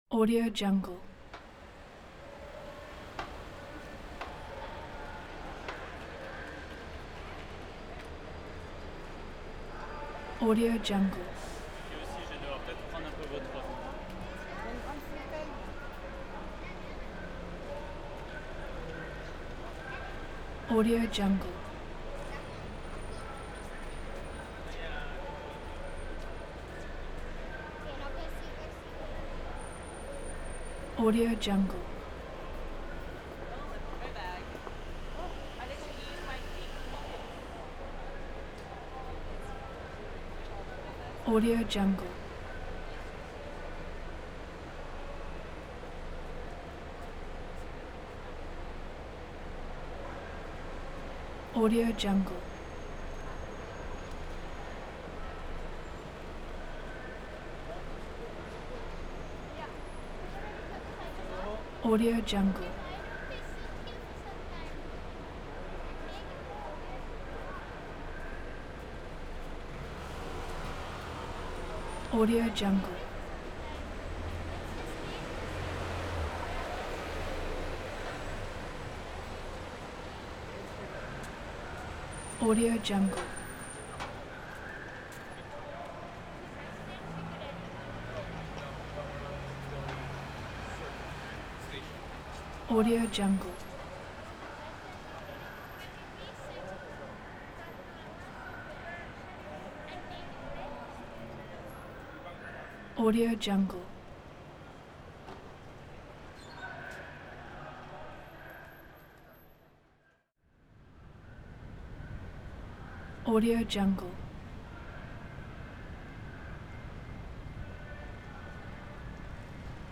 دانلود افکت صوتی آمبیانس شهری میدان کم تردد
City Square royalty free audio track is a great option for any project that requires urban sounds and other aspects such as an ambience, city and pedestrians.
Sample rate 16-Bit Stereo, 44.1 kHz
Looped No